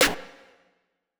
SNARE - GROWL.wav